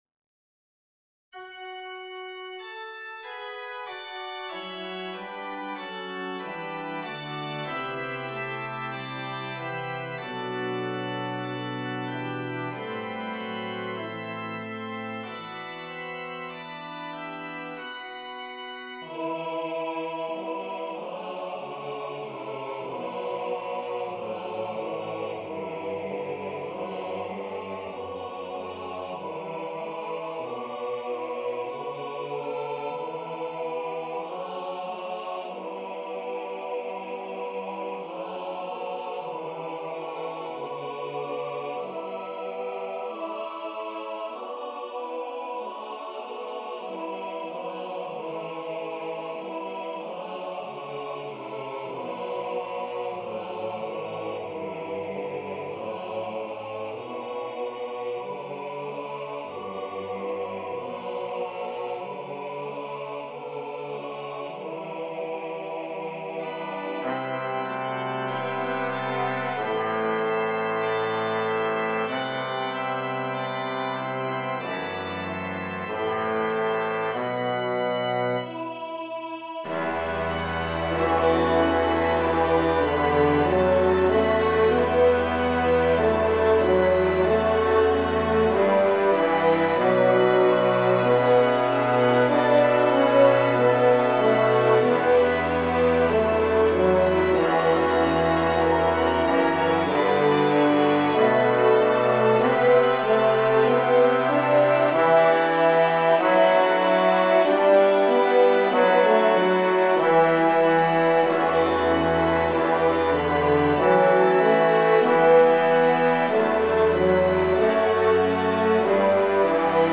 With organ
Tutti